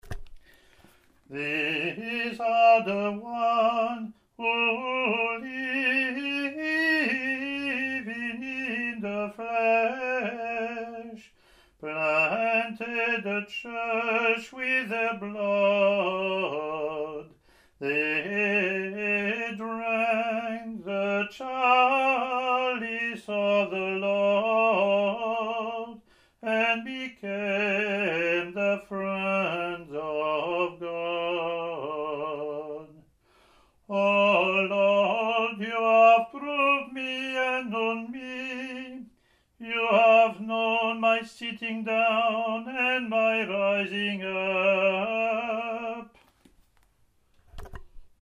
Download proper in English (same Gregorian mode):
English antiphon – English verse Latin antiphon and verse)